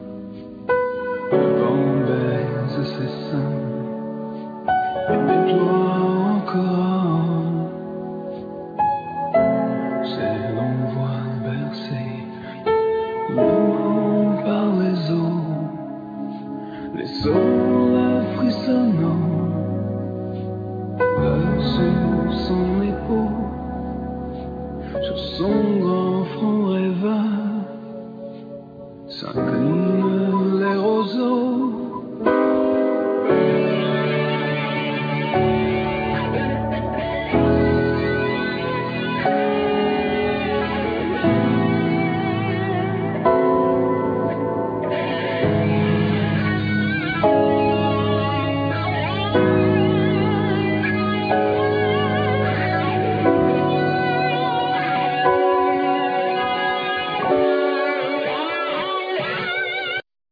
Piano,Electronics
Bass,Effects,Beats
Vocals,Bodhran,Percussions,Darbuka,Tin whistle
Harp,Sensuational backing vocals
Oud